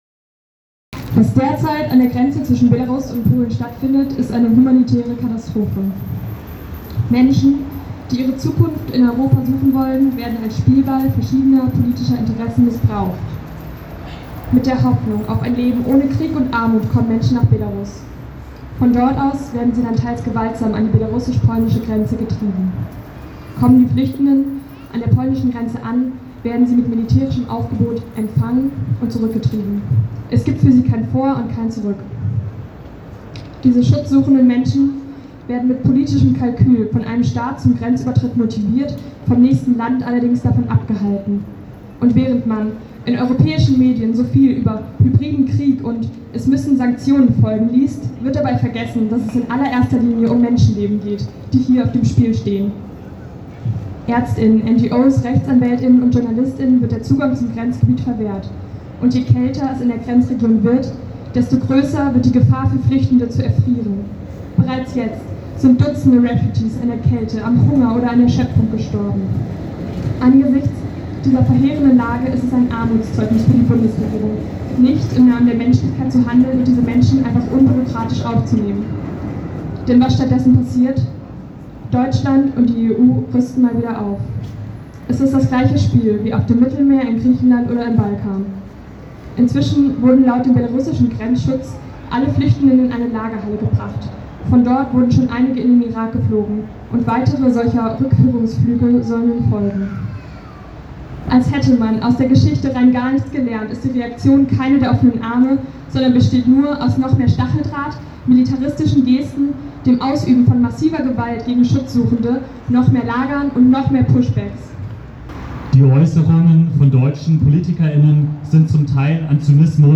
Heute, am Samstag den 20.11.2021 haben sich unter diesem Aufruf bei einer Kundgebung der Seebrücke über 80 Menschen am Europaplatz in Freiburg zusammengefunden.
Redebeiträge der Kundgebung